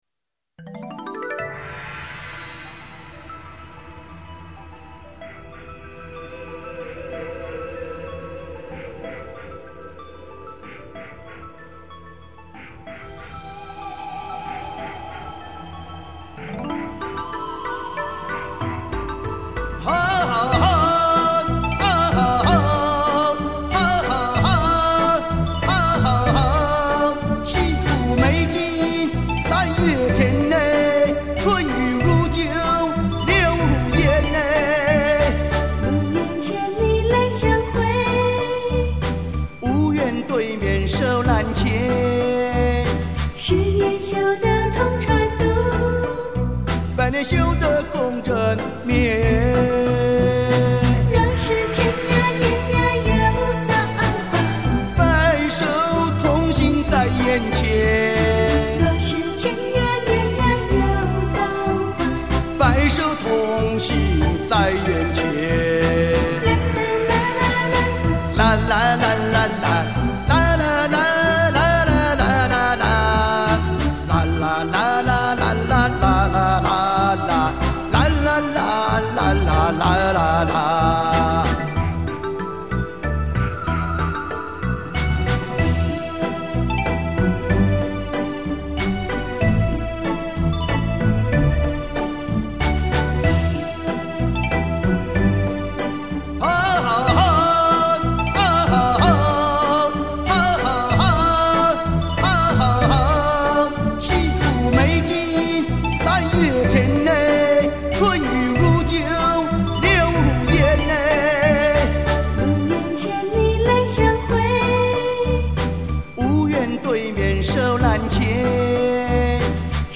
喜欢的专辑之一，剧中民风民情久印心中，尤其是对情爱的那份执着，好多场面催人泪下，曲调中民俗味特浓，我很是喜爱！